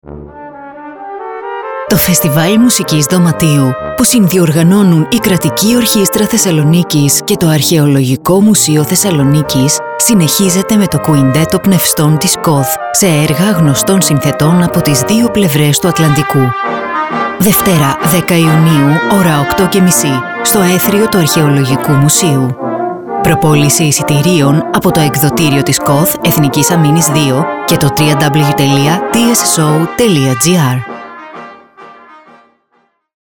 Ραδιοφωνικό σποτ 10ιουν24_Κουιντέτο χάλκινων πνευστών Κ.Ο.Θ. Κατηγορία: Ραδιοφωνικά σποτ Ηχος Ραδιοφωνικό σποτ 10ιουν24_Κουιντέτο Πνευστών ΚΟΘ.mp3 Σχετικό με τις εξής εκδηλώσεις: Κουιντέτο χάλκινων πνευστών Κ.Ο.Θ.
Ραδιοφωνικό σποτ 10ιουν24_Κουιντέτο Πνευστών ΚΟΘ.mp3